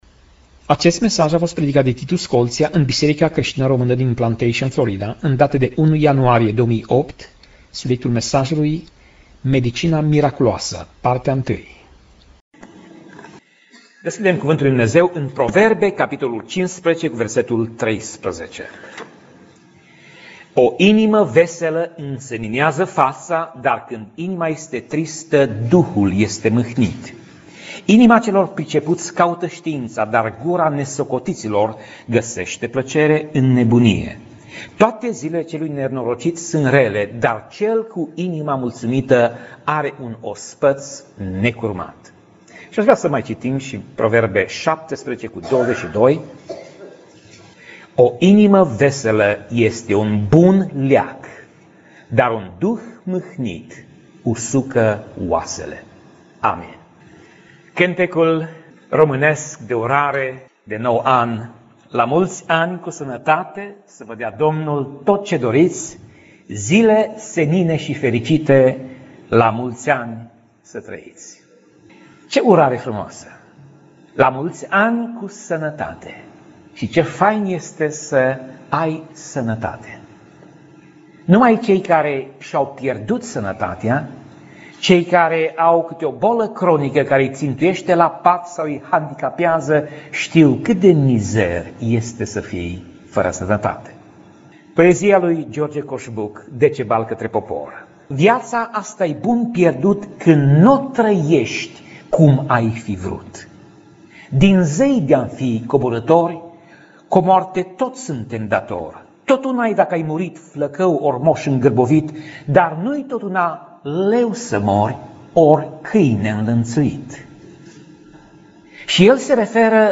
Tip Mesaj: Predica